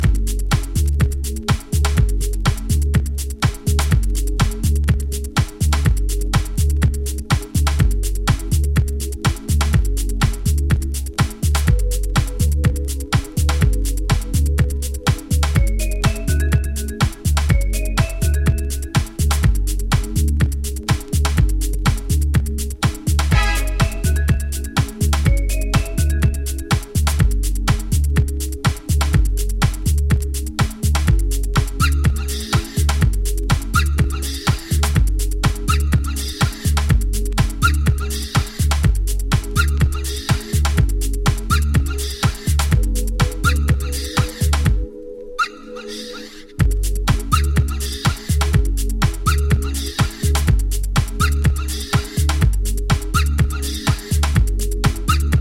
抜けの良い程よくジャジーでスムースなトラックに、軽快なラガマフィンが絡む好トラックです！
TECHNO & HOUSE / BACK TO BASIC